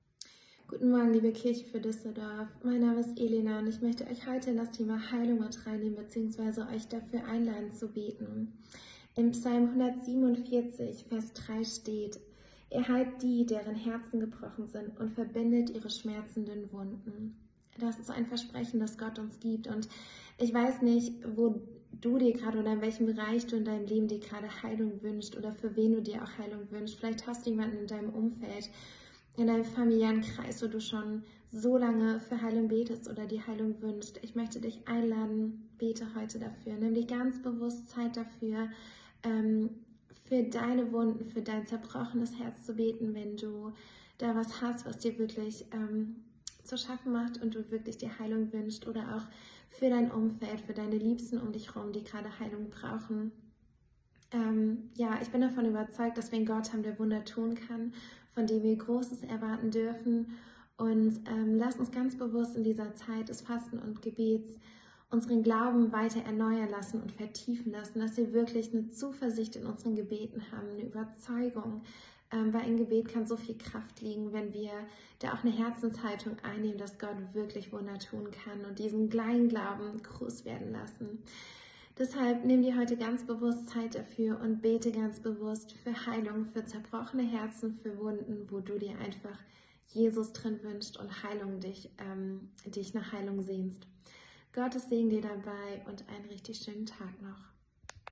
Andacht zu unseren 21 Tagen des Gebets